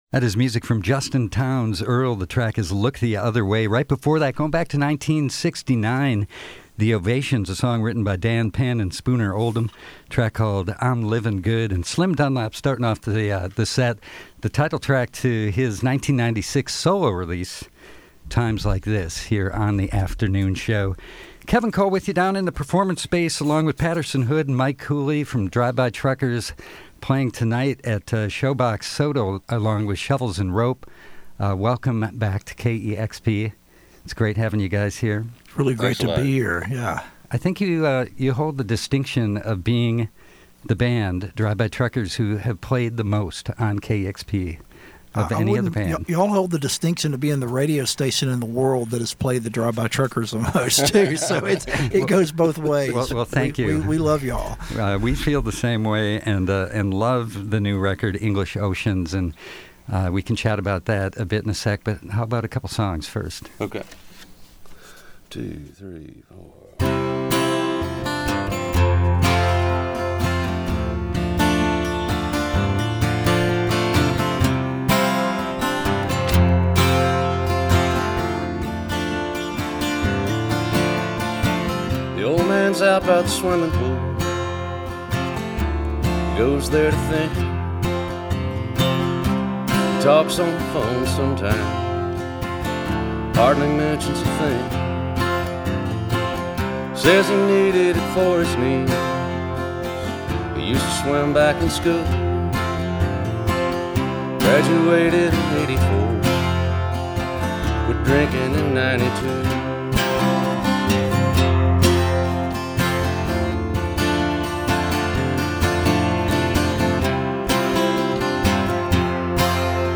Southern rock
with a stripped-down acoustic performance.